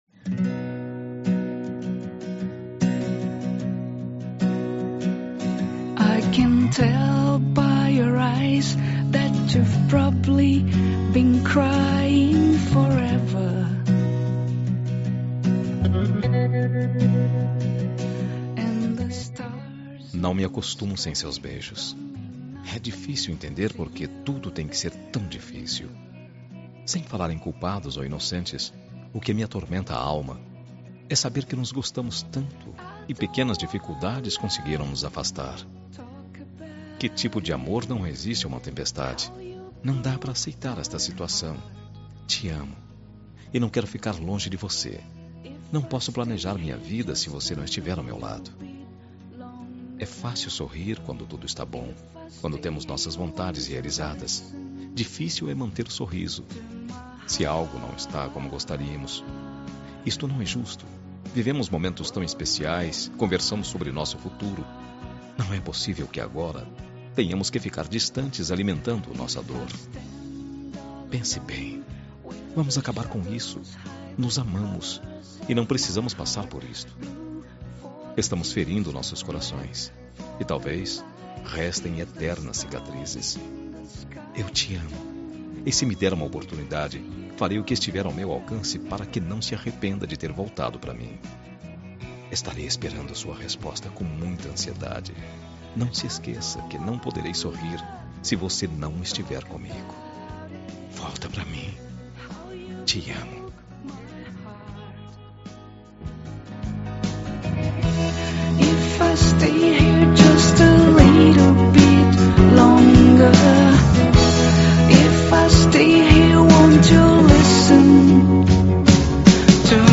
Telemensagem de Reconciliação – Voz Masculina – Cód: 7556